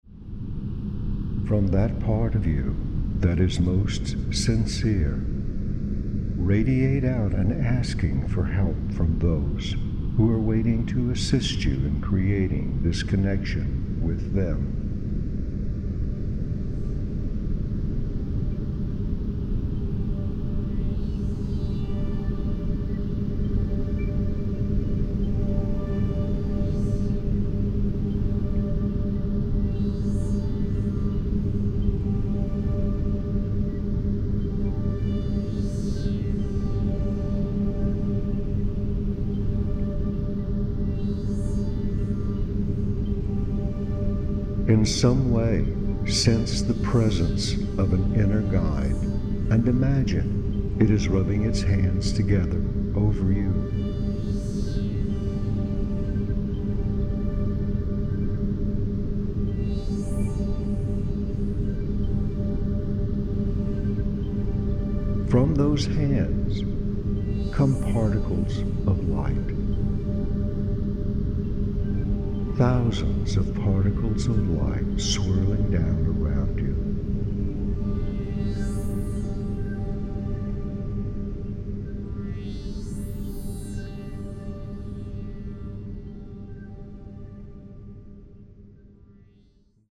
Verbální vedení: Anglické verbální vedení Využití: Duchovní růst, spojení s vnitřním průvodcem.